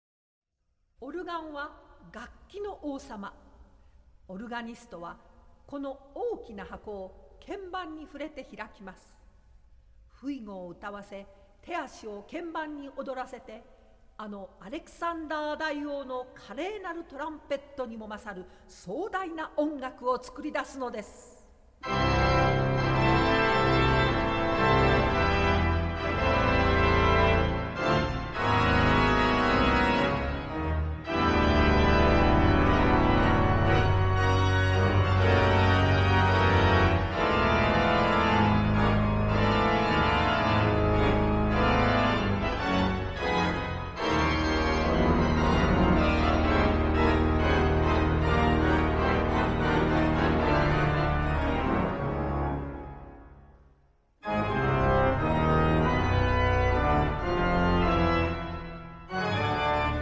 organ
percussion
Japanese narrator